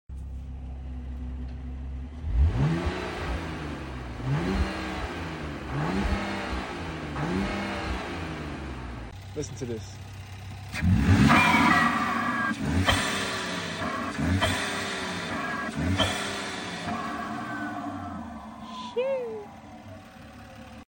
Ram 2025 Inline6 Twin Turn Sound Effects Free Download